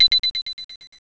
download-complete.wav